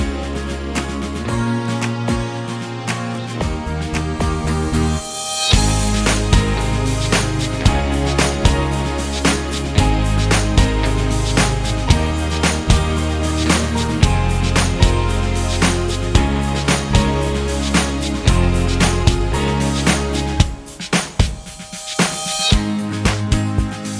no Backing Vocals Jazz / Swing 3:18 Buy £1.50